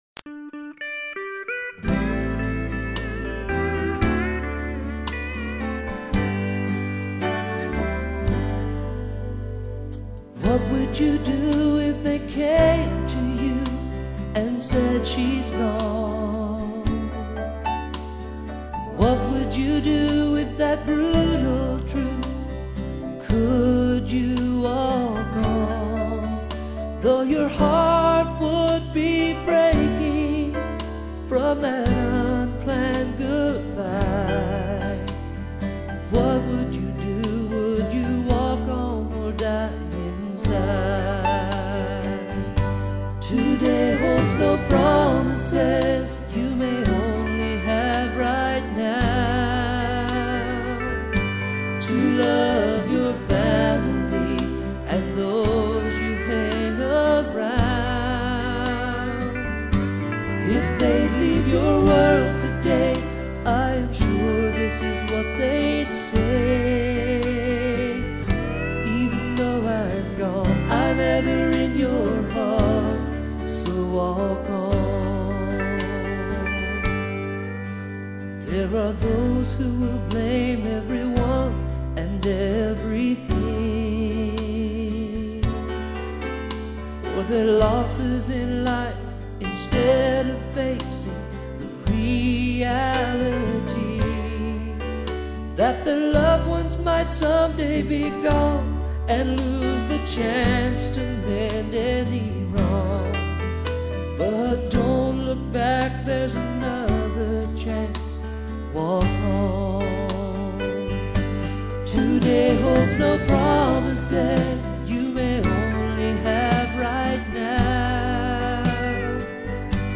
Southern Gospel Songwriter